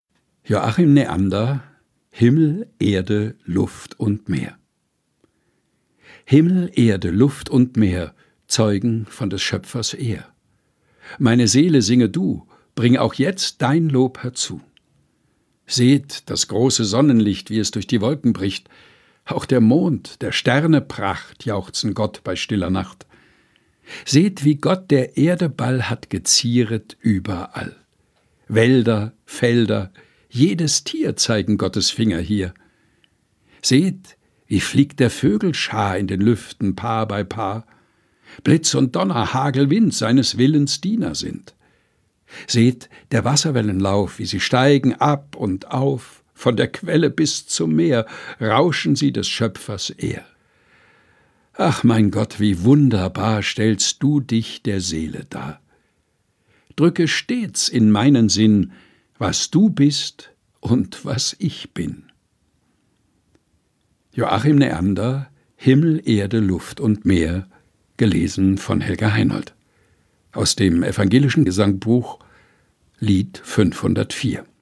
im Dachkammerstudio vorgelesen